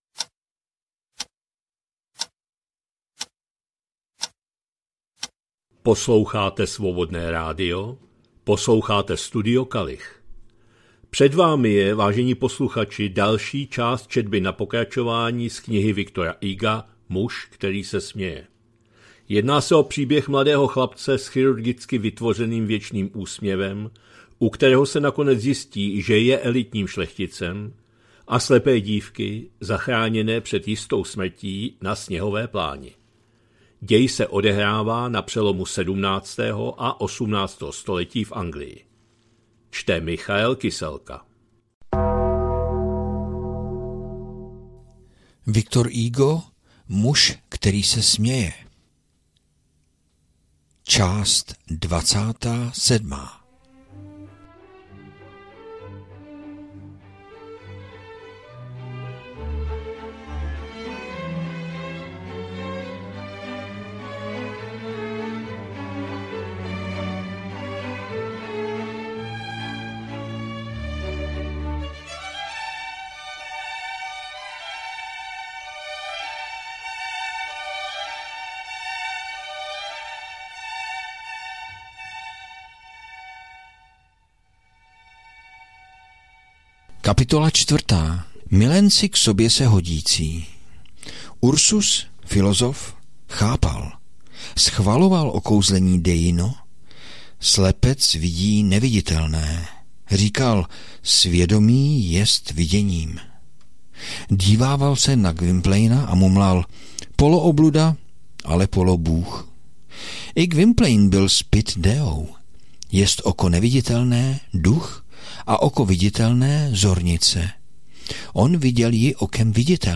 2025-09-22 – Studio Kalich – Muž který se směje, V. Hugo, část 27., četba na pokračování